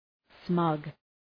Προφορά
{smʌg}